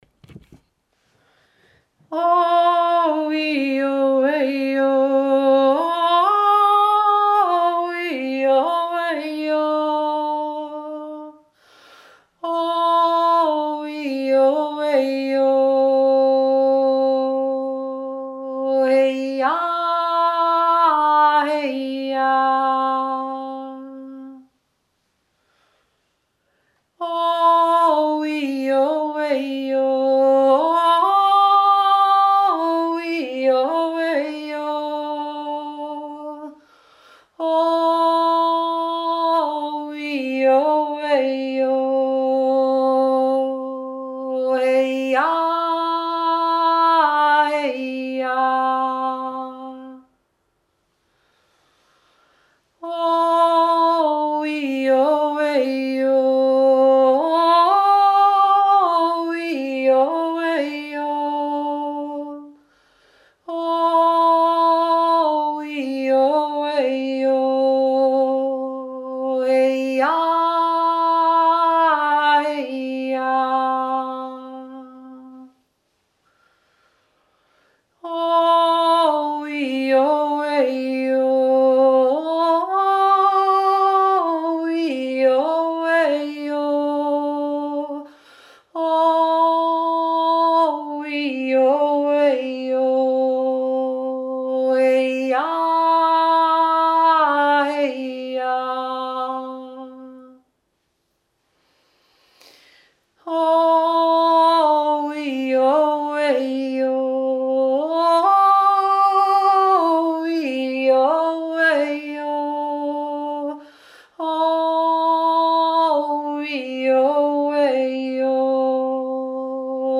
O wio weo indianischer Chant
1. Stimme
2. Stimme